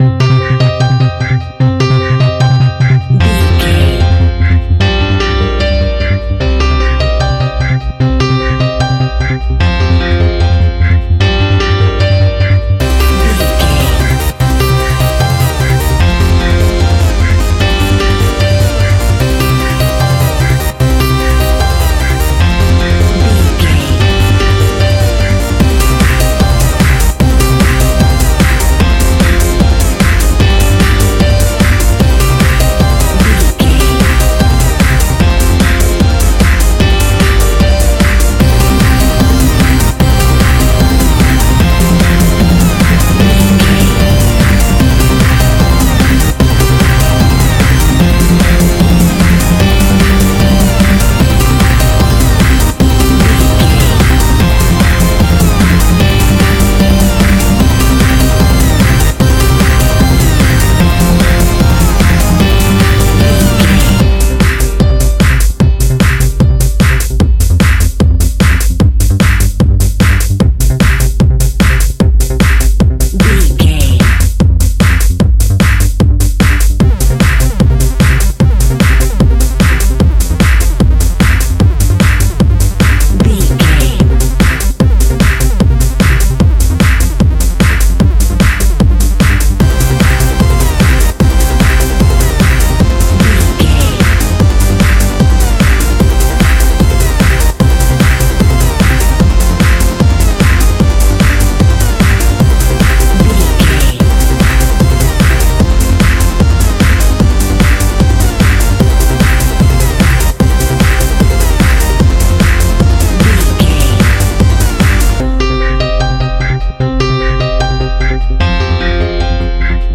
Sounds Like Trance Full Mix.
Aeolian/Minor
Fast
driving
energetic
hypnotic
industrial
drum machine
synthesiser
piano
uptempo
synth leads
synth bass